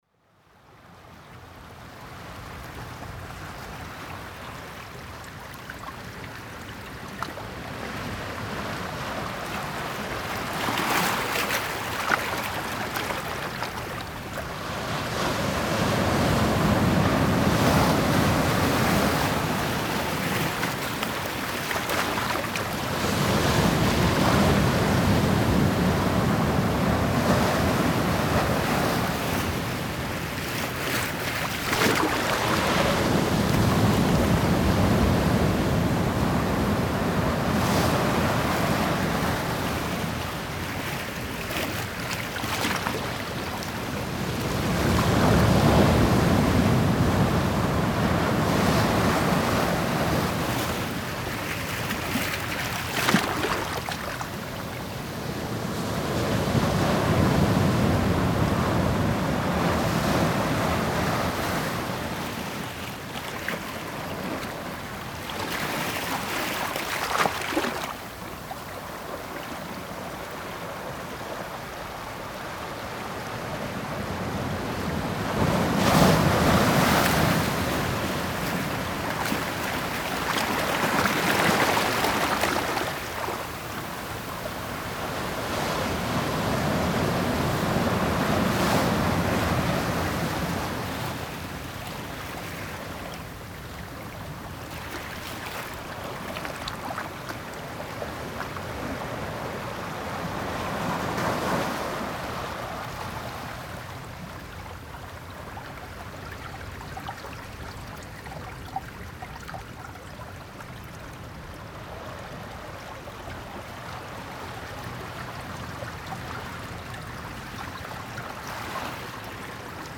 Gemafreie Sounds: Wasser - Küste
mf_SE-6387-cliff_3.mp3